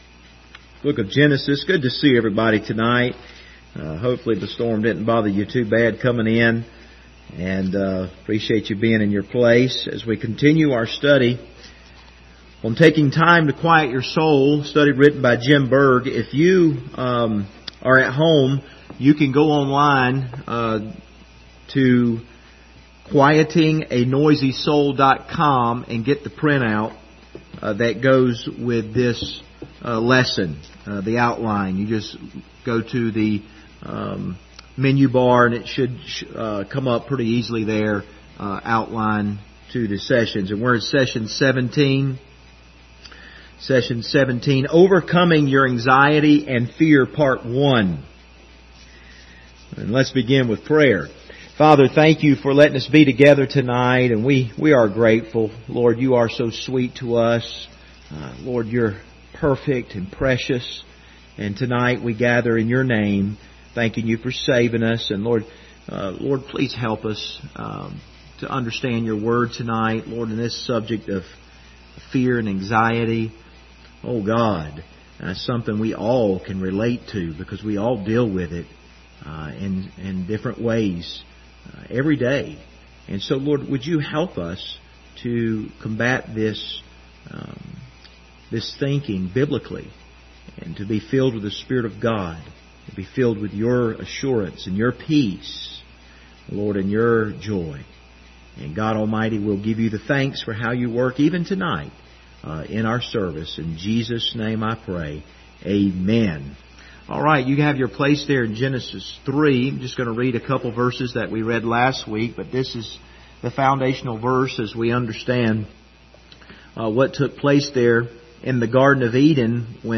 Service Type: Wednesday Evening Topics: anxiety